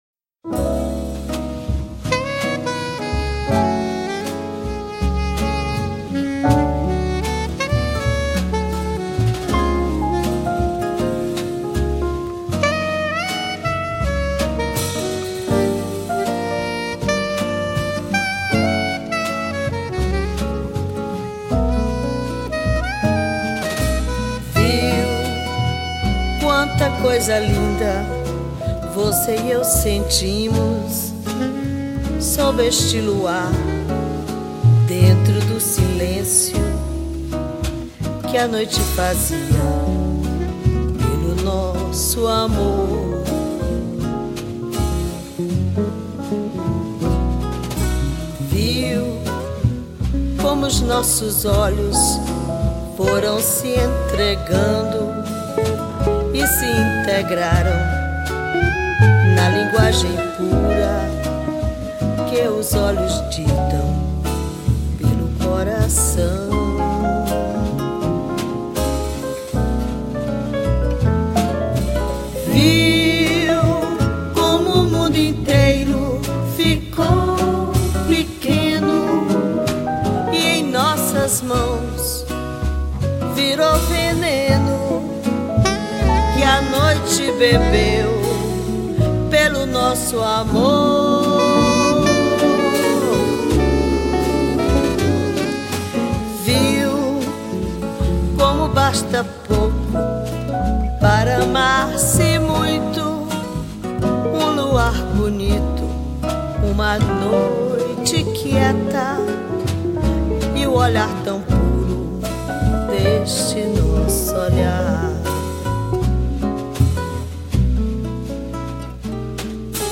1394   06:14:00   Faixa:     Samba Canção